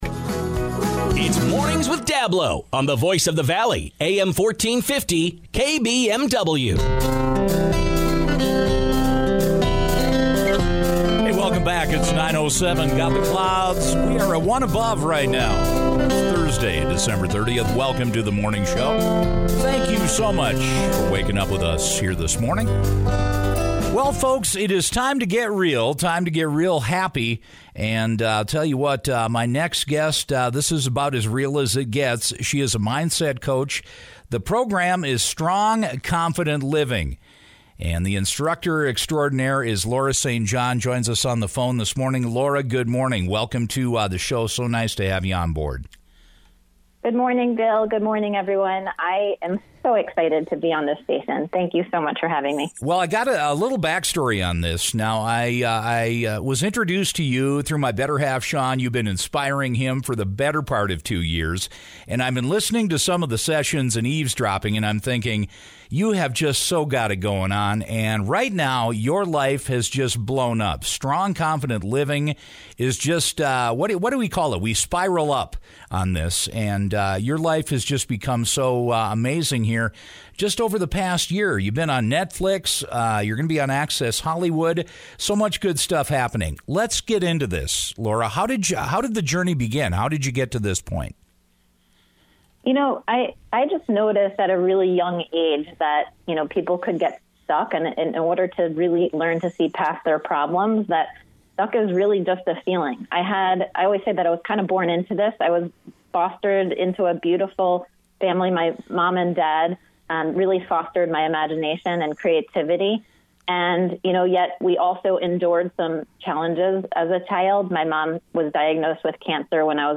She stressed that happiness needs to come from the inside. To cultivate it, we need to “flip” our perceptions from negative to positive. Listen to the interview by clicking on the podcast below.